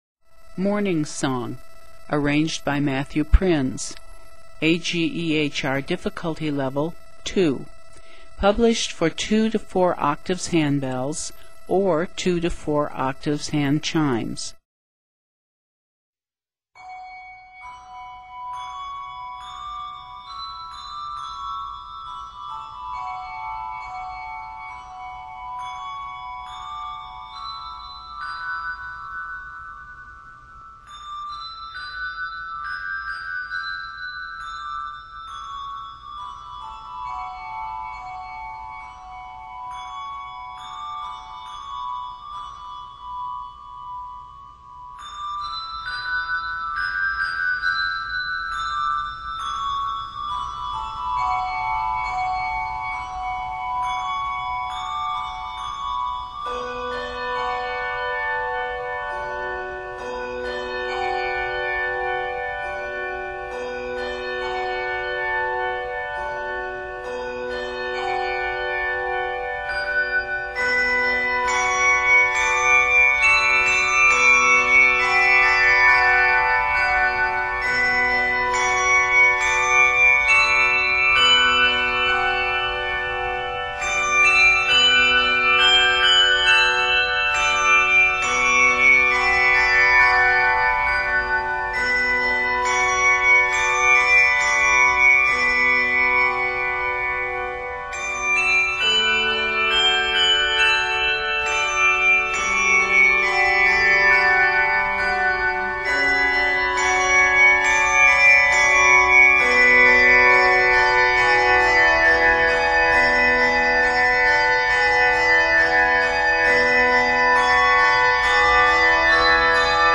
Octaves: 2-4